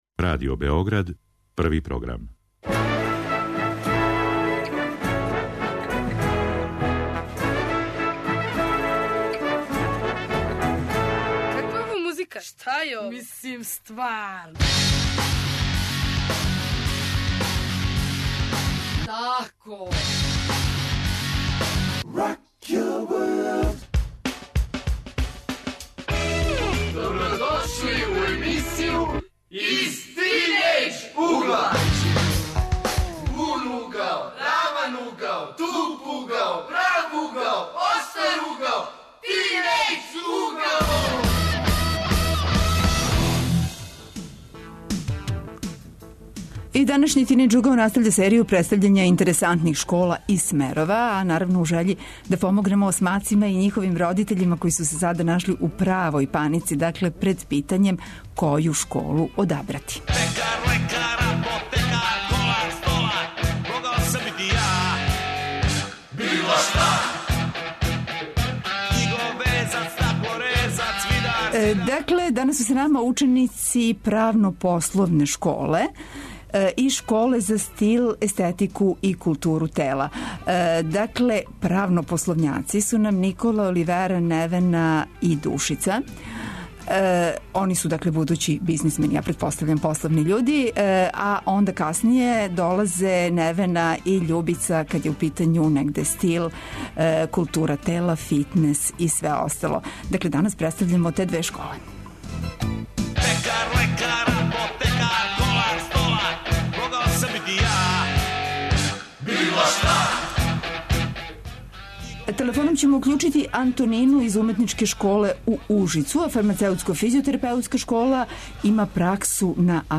Путем телефона представиће нам се и Уметничка школа из Ужица, Грађевинско - уметничка из Новог Сада, а наш репортер јавиће се с Трга Републике у Београду где ће се, такође, представити средње школе.